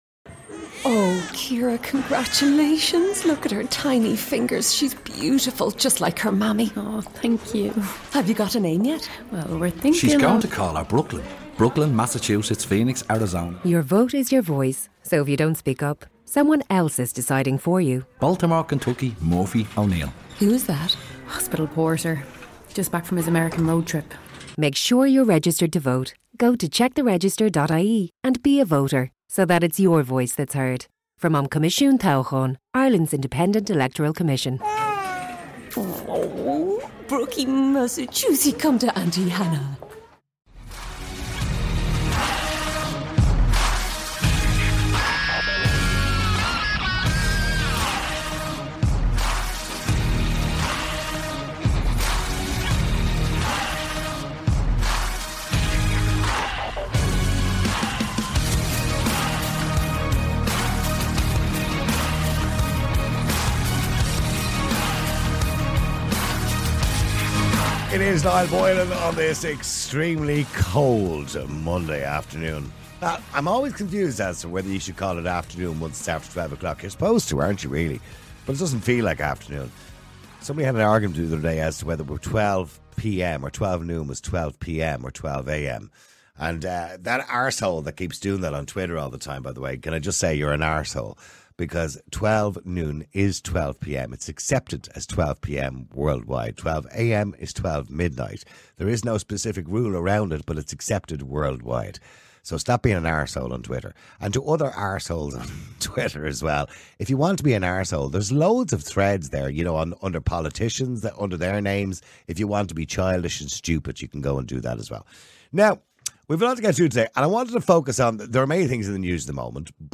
Callers share their perspectives on the dangers posed by the online landscape, including the rise of online predators and governmental surveillance, which some argue create an unsafe environment for children.